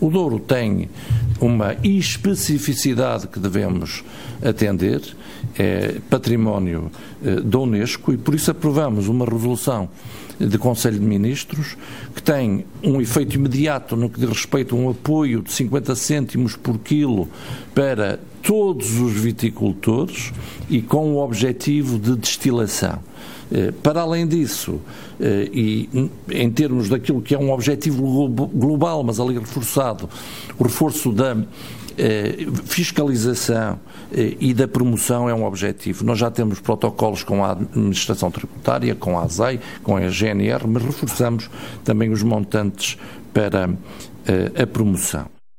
Na conferência de imprensa que se seguiu ao Conselho de Ministros, José Manuel Fernandes, ministro da Agricultura, disse que o Douro tem especificidades que justificam medidas de apoio diferentes: